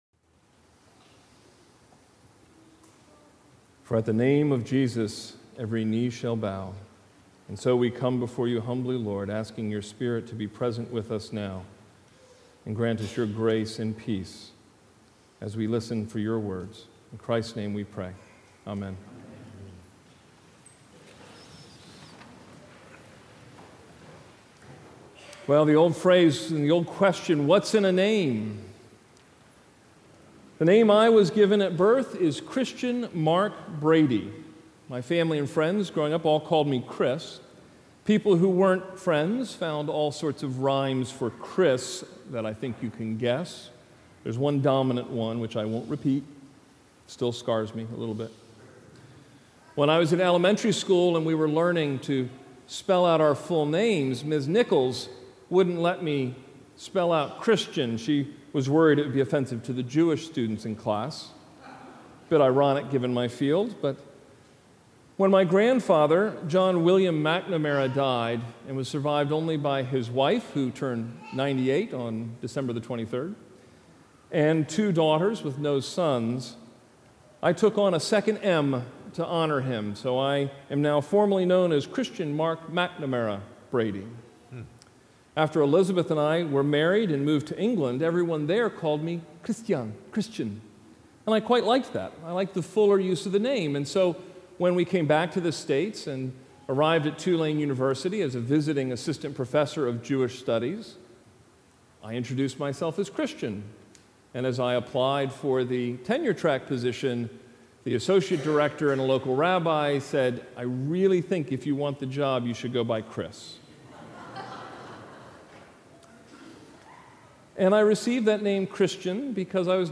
The sermon from this past Sunday, January 1, 2017 – The Holy Name of Jesus.